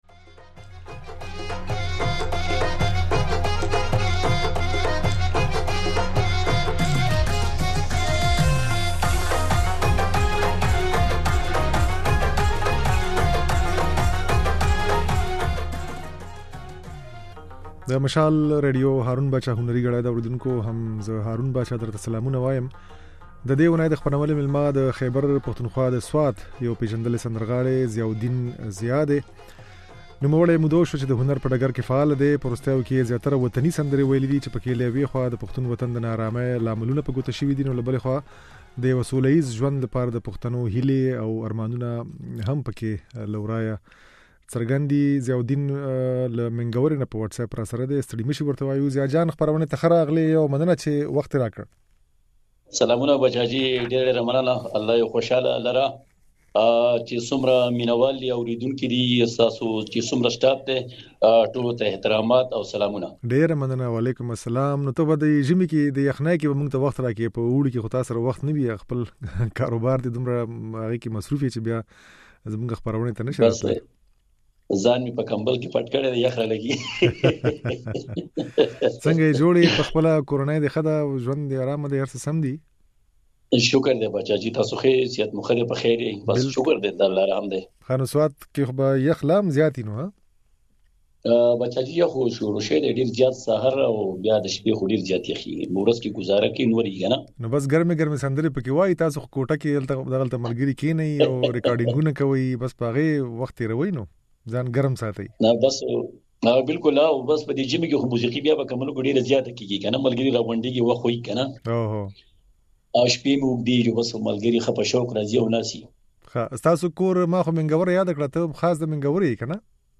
ځينې سندرې يې په خپرونه کې اورېدای شئ